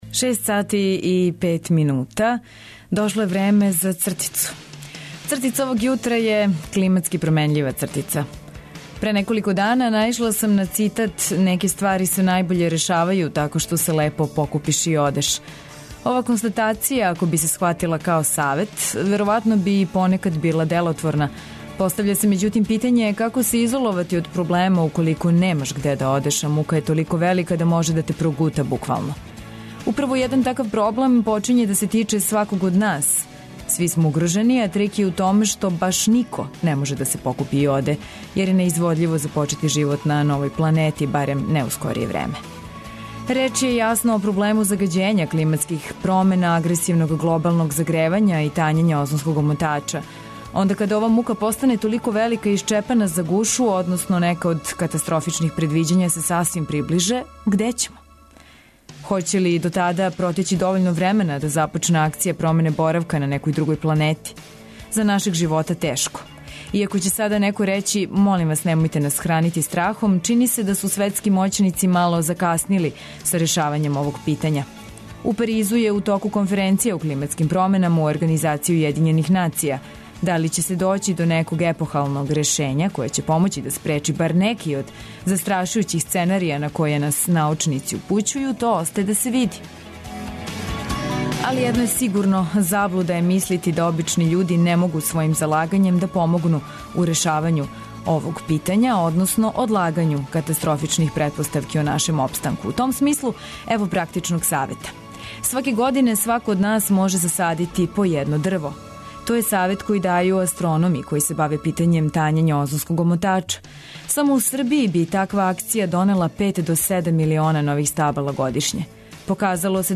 Водитељ